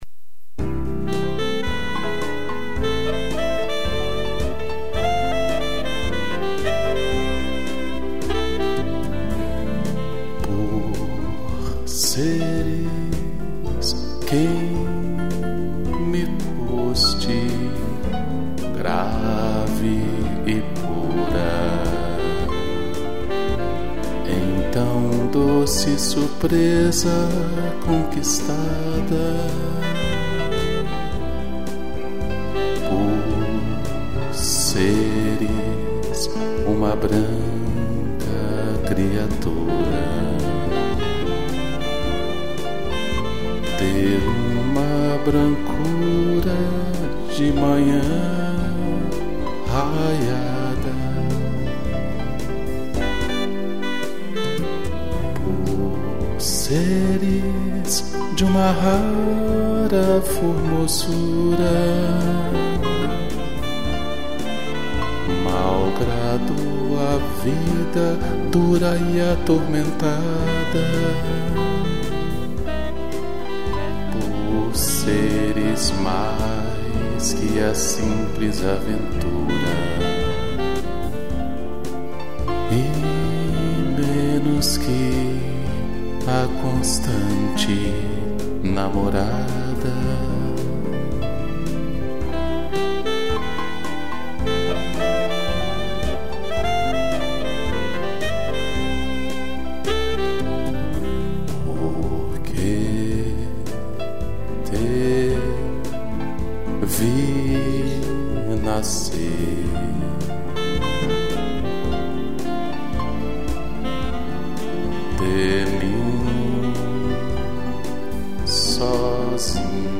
piano, strings e sax